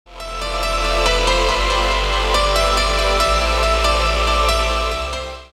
громкие
спокойные
без слов
клавишные
Trance